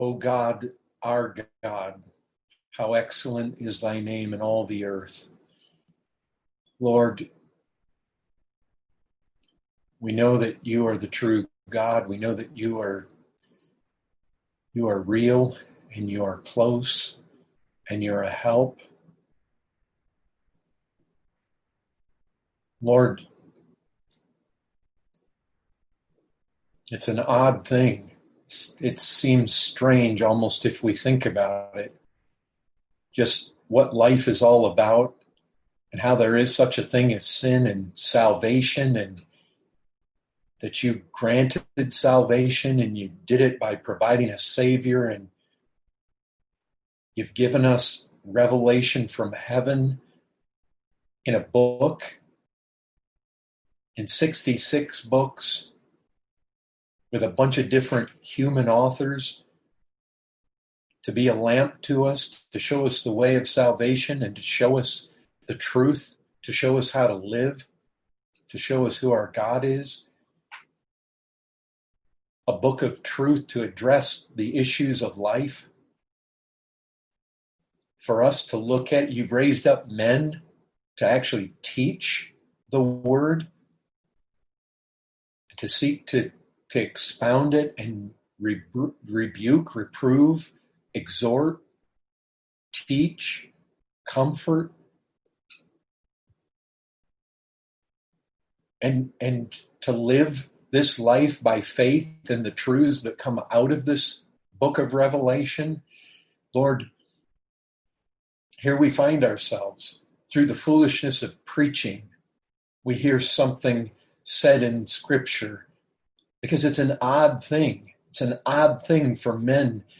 2020 Category: Questions & Answers 1